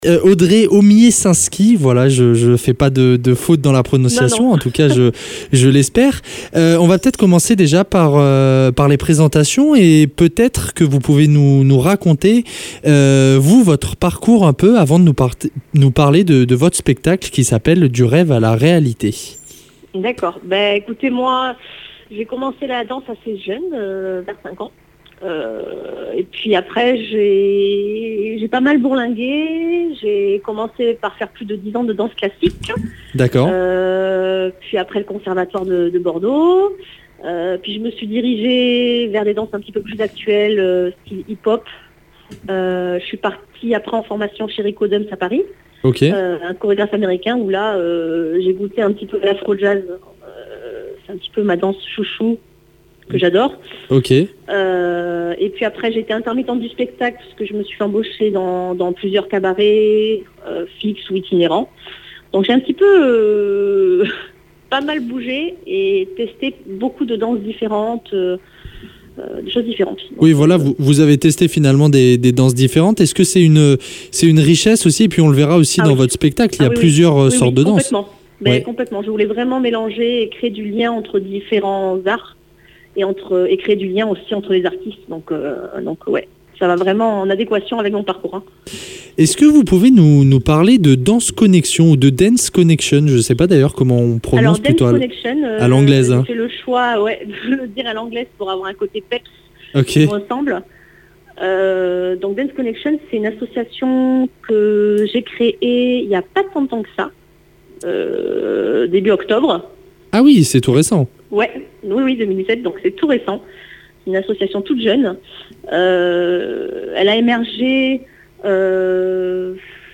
Interview !